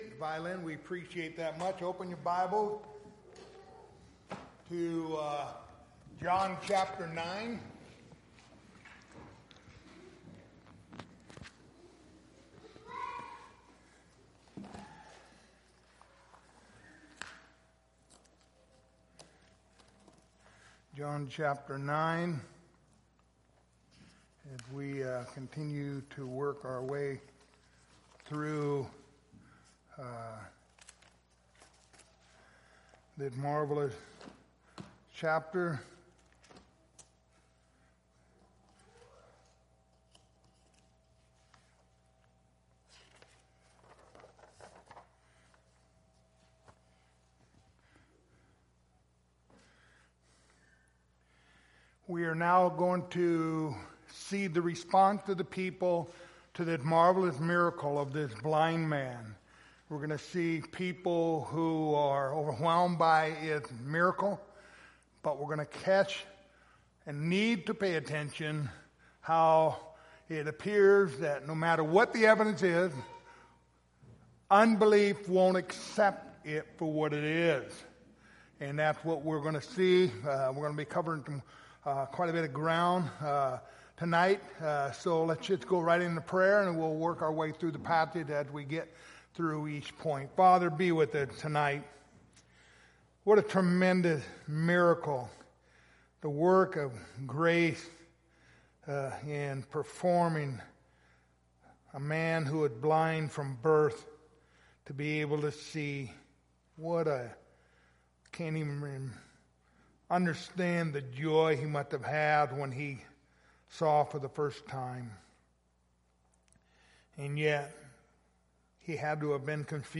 Passage: John 9:13-34 Service Type: Wednesday Evening